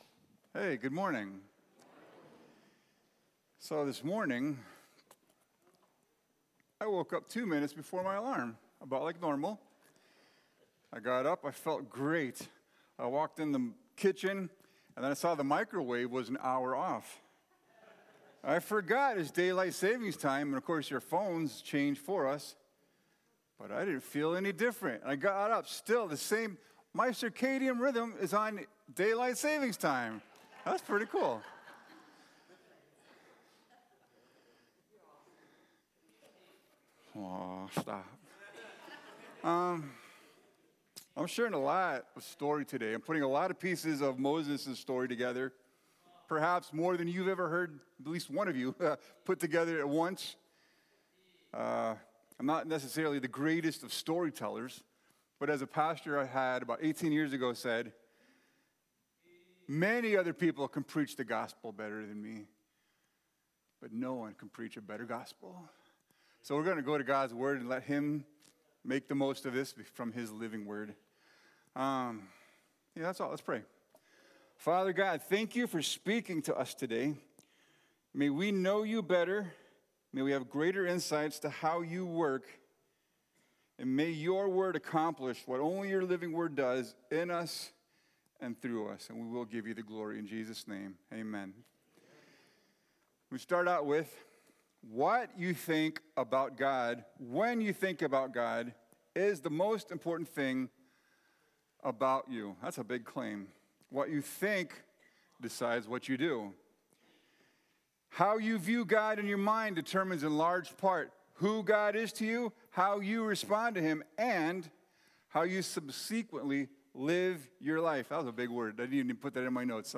Sermon-3-8-26.mp3